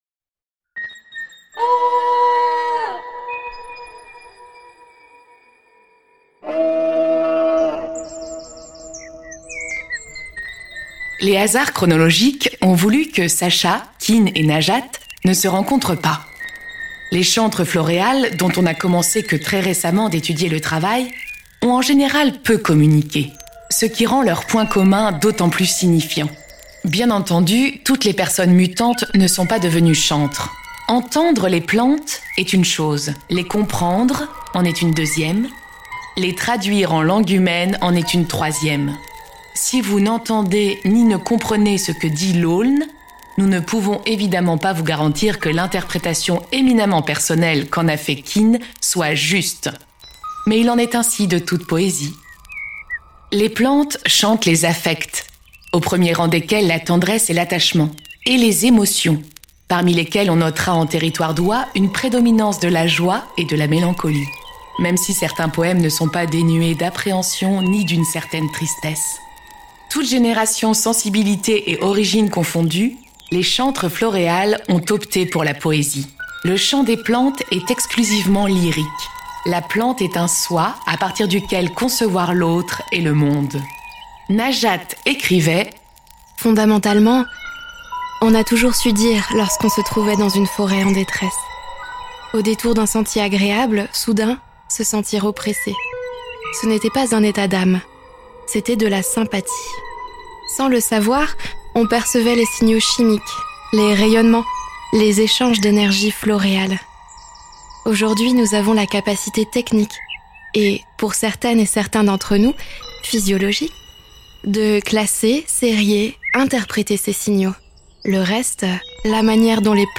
Poetry . Utopia . Interspecies
Ouwa is a geolocated soundwalk around the Forges pond, offering an immersive experience that makes living things audible.